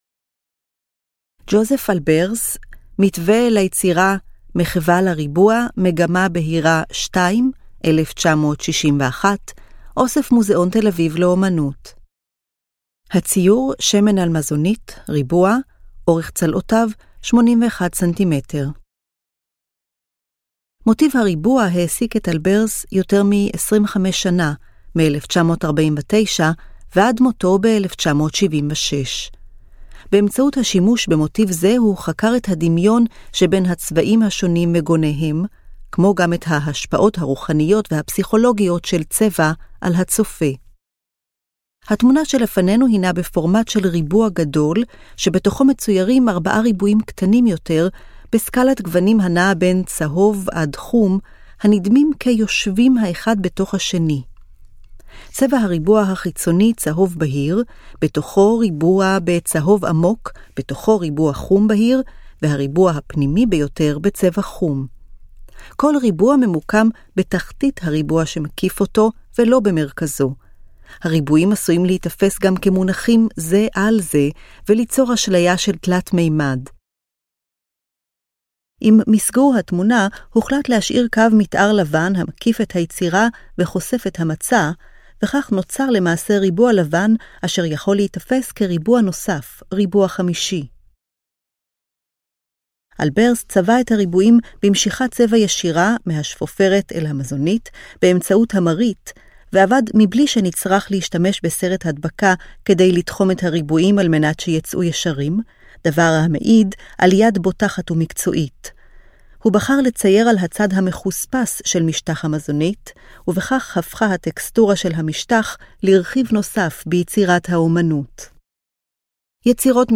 היצירות כולן הונגשו כאמור באמצעות: טקסט – תיאור מורחב המתאר את פרטי היצירה, אודיו – הקלטת התיאור המורחב אותו ניתן לשמוע במדריך הקולי של המוזיאון, גרפיקה טקטילית - הבלטה של היצירות באמצעות מדפסות ברייל, ברייל – תרגום הטקסט המורחב לכתב ברייל.